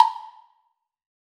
PWBLOCK.wav